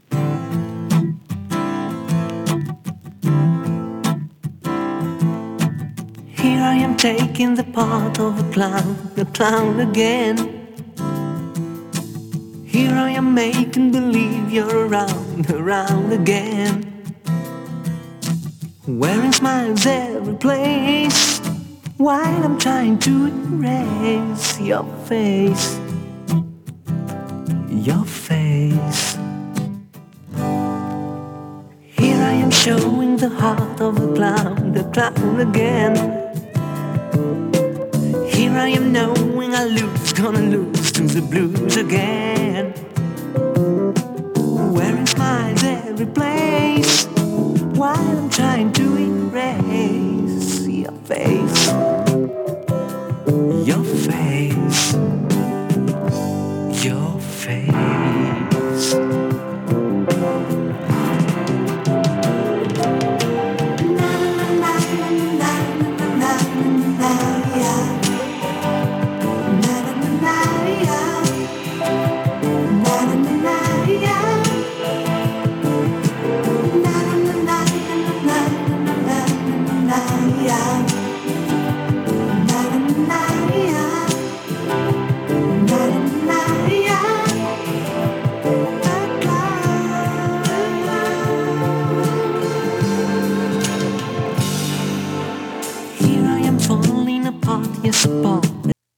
フランス南部はトゥールース出身のシンガーによるファースト・アルバム。